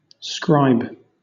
Ääntäminen
Southern England Tuntematon aksentti: IPA : /skɹaɪb/ RP : IPA : [skɹaɪ̯b] US : IPA : [skɹaɪ̯b] IPA : [skɹɑɪ̯b] CA : IPA : [skɹaɪ̯b] IPA : [skɹəjb] AU : IPA : [skɹɑe̯b] NZ : IPA : [skɹɑe̯b] SSBE: IPA : [skɹɑjb]